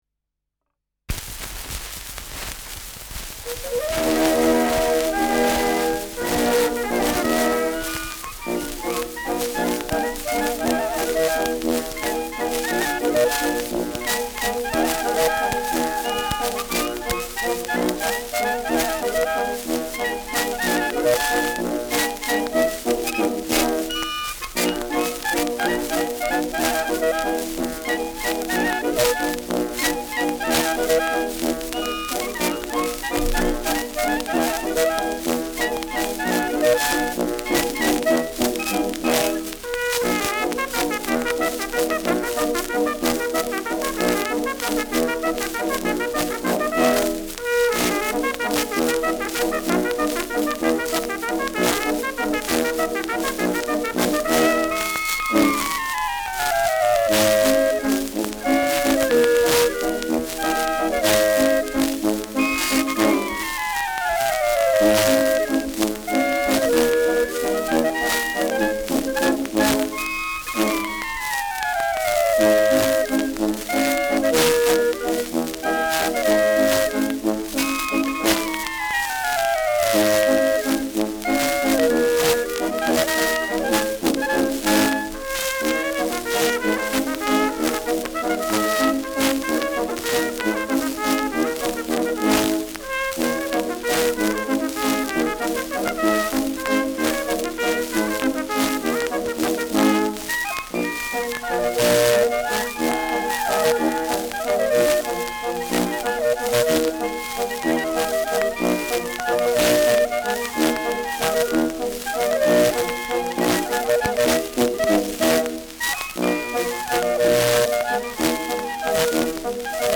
Schellackplatte
präsentes Rauschen
Mit Juchzern.